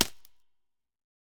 Minecraft Version Minecraft Version snapshot Latest Release | Latest Snapshot snapshot / assets / minecraft / sounds / mob / creaking / creaking_freeze2.ogg Compare With Compare With Latest Release | Latest Snapshot
creaking_freeze2.ogg